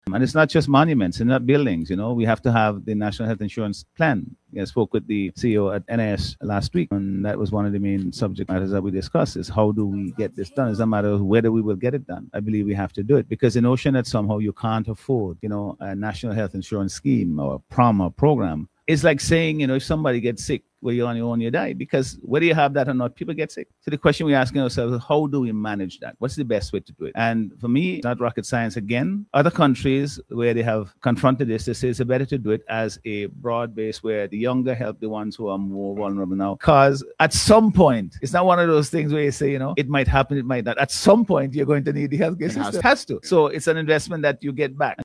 That declaration has come from Prime Minister Dr. Godwin Friday who was speaking on radio recently.